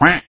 With the co-operation of my duck, “Arnold,” I recorded his quack.
quack1.wav